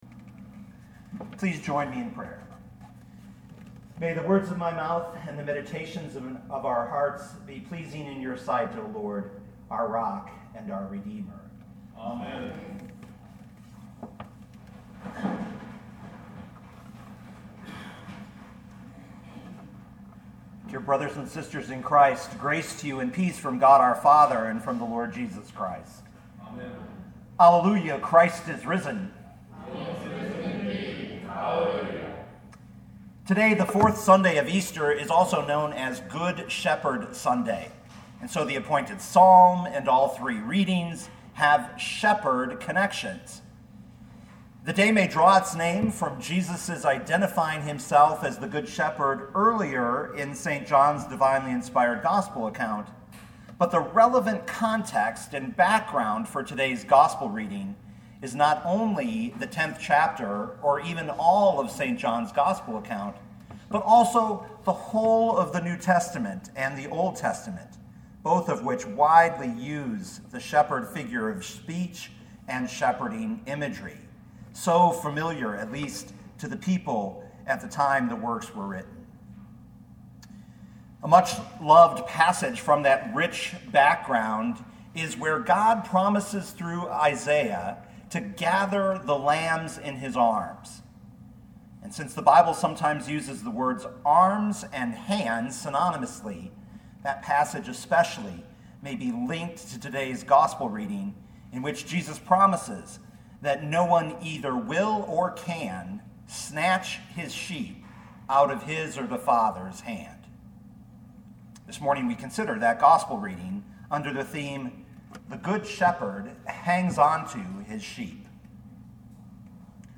2019 John 10:22-30 Listen to the sermon with the player below, or, download the audio.